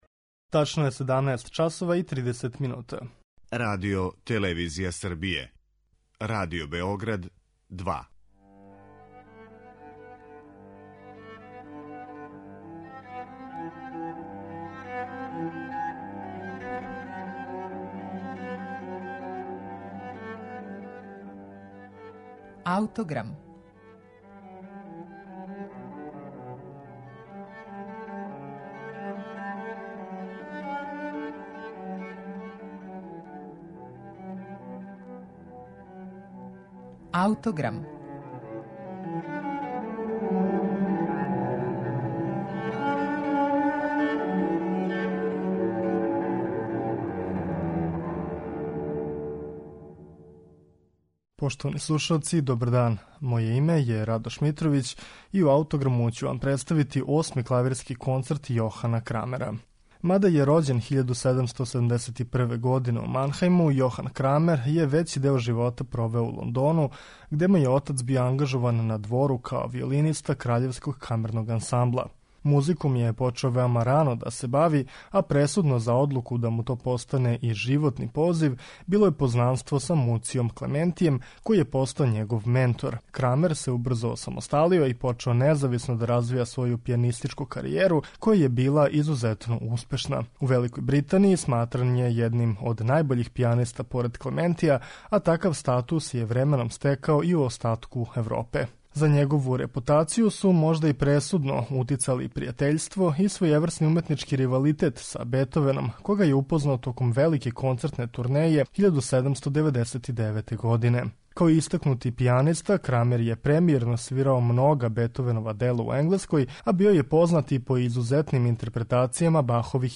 пијанисте